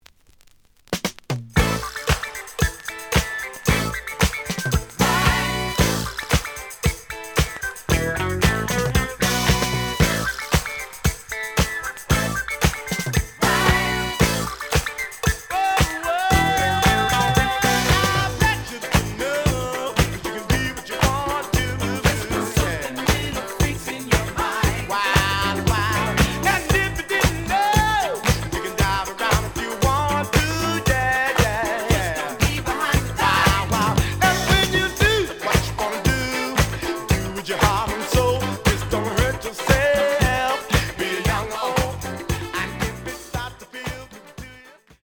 The audio sample is recorded from the actual item.
●Genre: Disco
Some damage on both side labels. Plays good.)